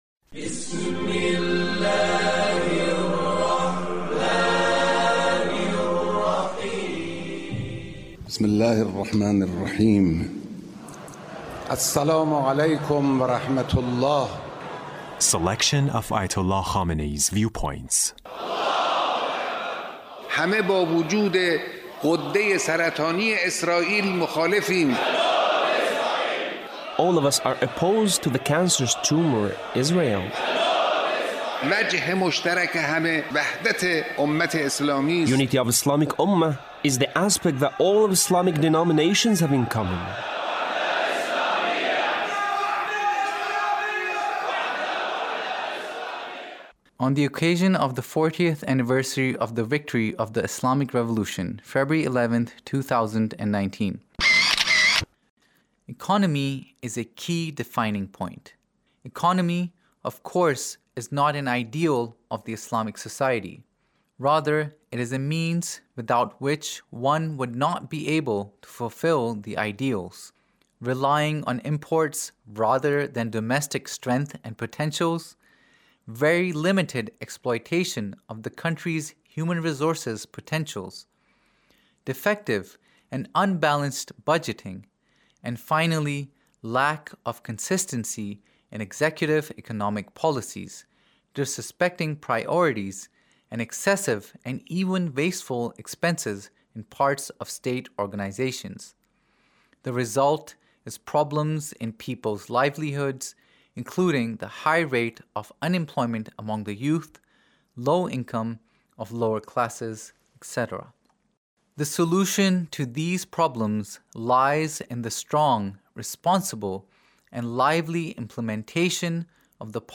Leader's Speech (1895)